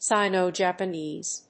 音節Sì･no-Japanése発音記号・読み方sàɪnoʊ-
sino-japanese.mp3